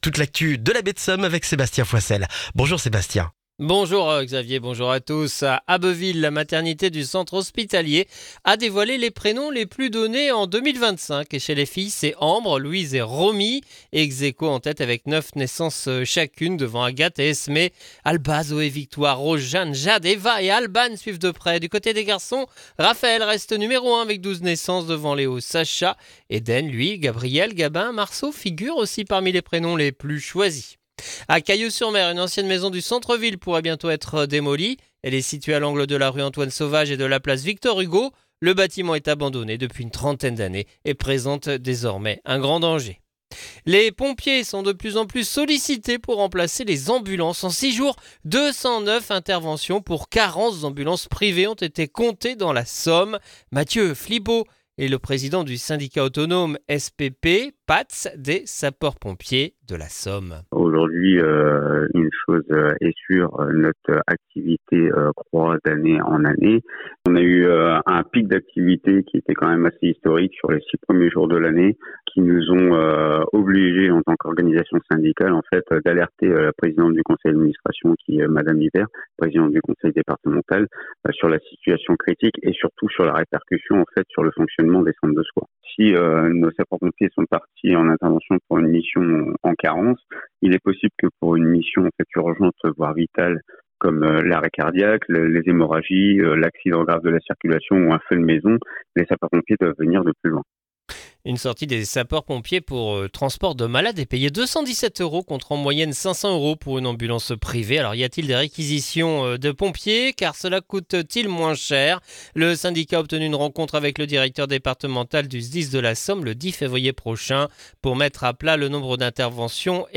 Le journal du lundi 26 janvier en Baie de Somme et dans la région d'Abbeville